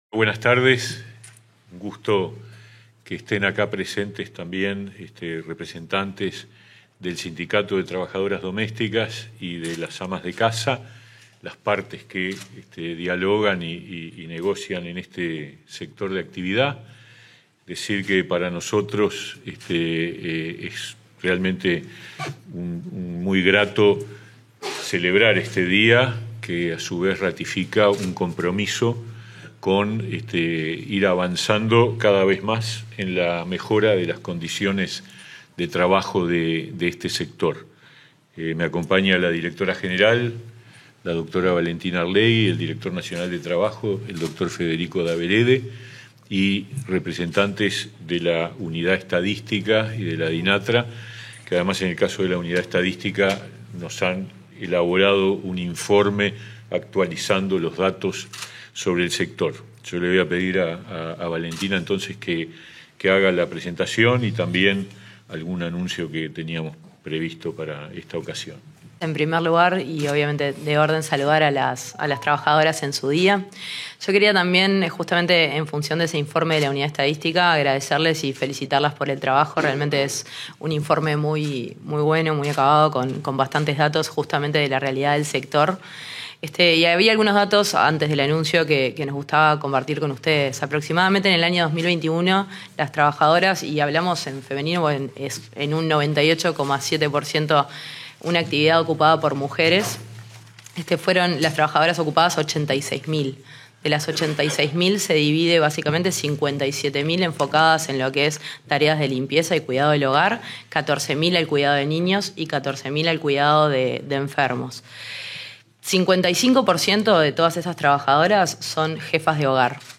Conferencia de prensa por el Día de la Trabajadora Doméstica
Conferencia de prensa por el Día de la Trabajadora Doméstica 19/08/2022 Compartir Facebook X Copiar enlace WhatsApp LinkedIn El ministro de Trabajo y Seguridad Social, Pablo Mieres; la directora general de la cartera, Valentina Arlegui, y el director nacional de Trabajo, Federico Daverede, se expresaron en conferencia de prensa, este 19 de agosto, con motivo del Día de la Trabajadora Doméstica.